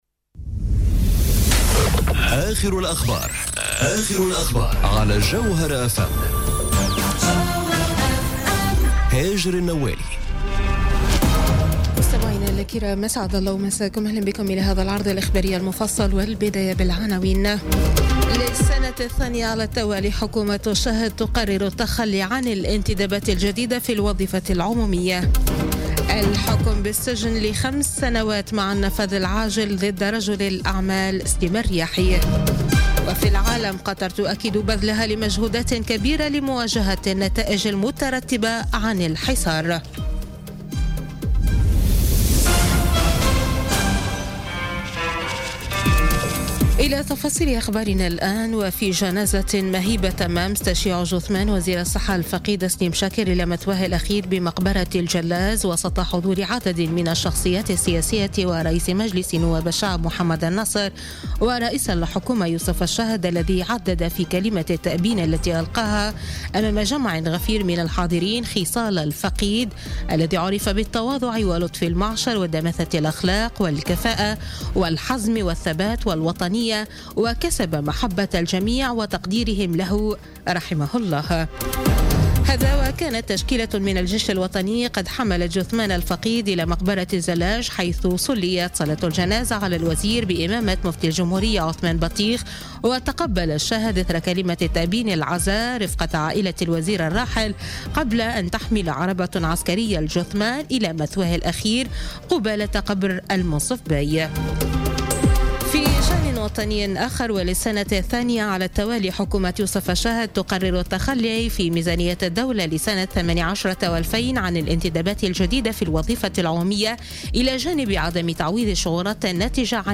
نشرة أخبار منتصف الليل ليوم الثلاثاء 10 أكتوبر 2017